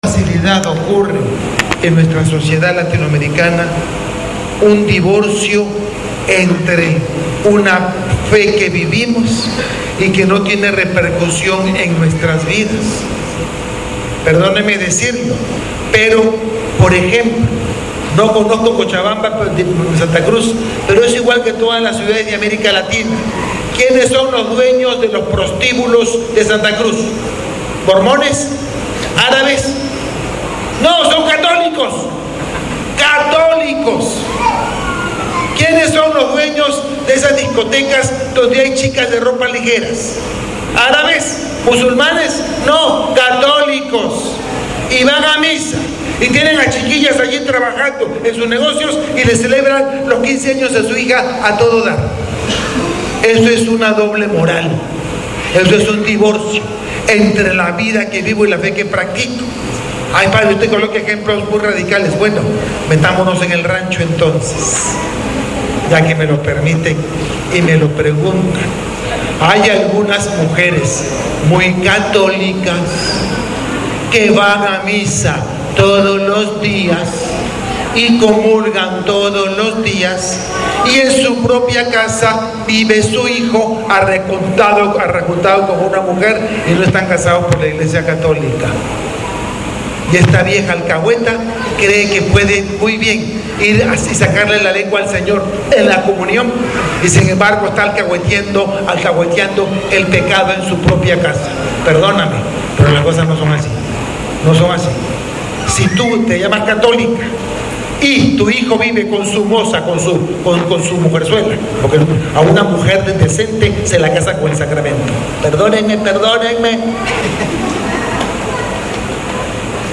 Homilia del Lunes 4/3/19